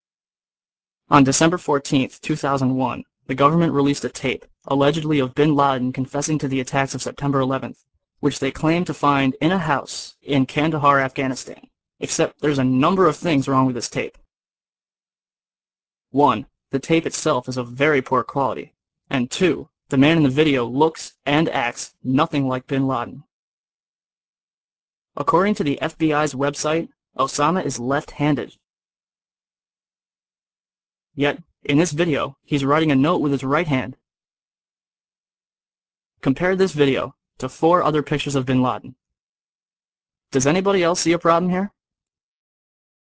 ( ABC NBC ) Hear 2 guys talk about this video as if it was genuine!